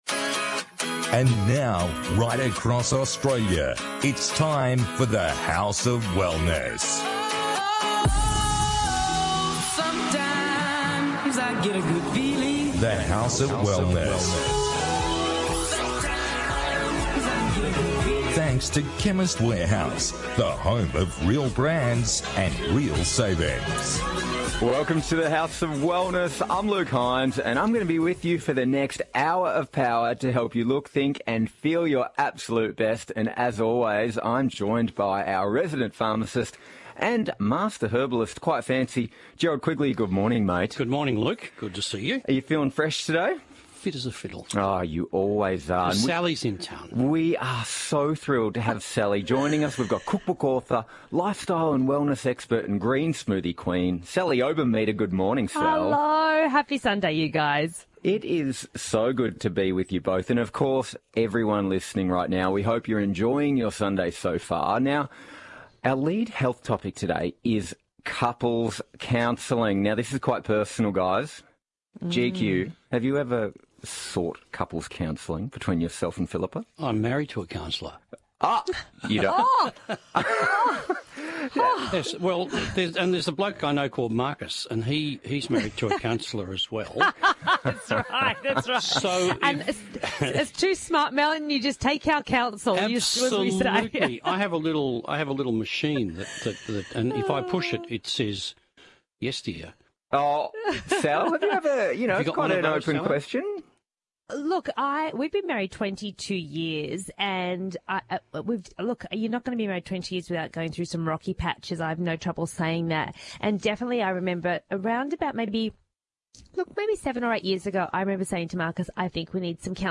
On this week’s The House of Wellness radio show: